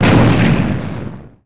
gen_small_explo_01.wav